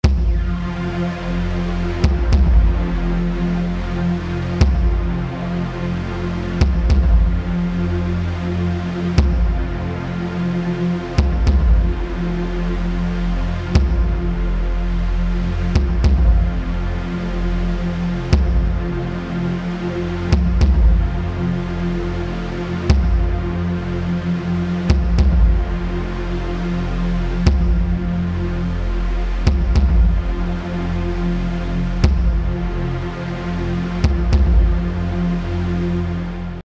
Eine andere beliebte Spielart ist die Rhythmisierung eines Flächensounds, den man durch einen Beat/Percussion-Track komprimiert.
Zunächst die Bassdrum und der Flächensound (aus Omnisphere 2) ohne den Drawmer 1973 …